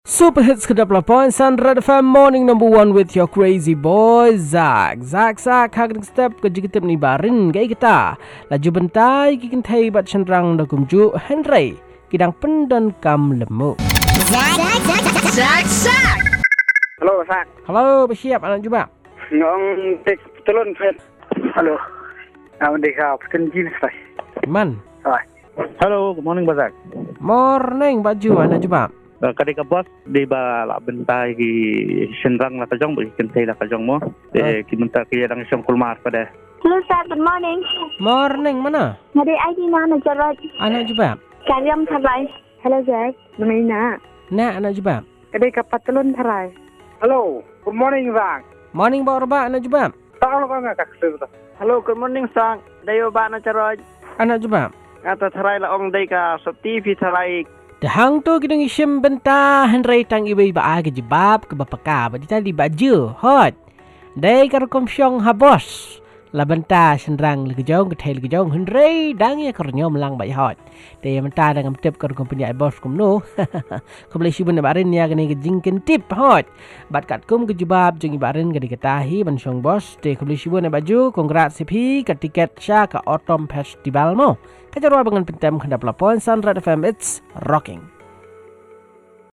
calls and results